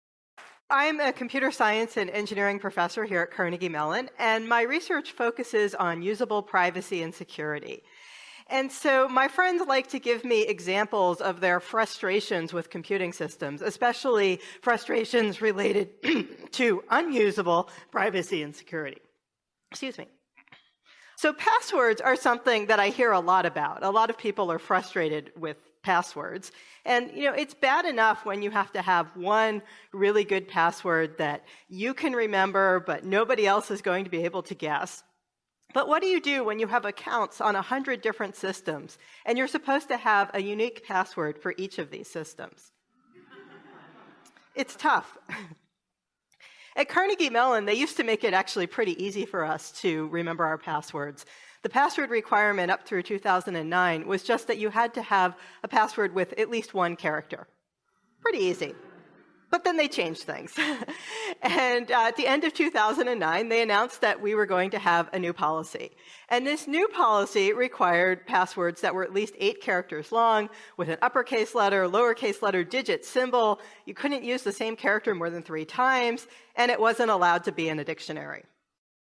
You will hear a lecture.
COMPUTER SCIENCE LECTURE
Computer-science-Lecture.mp3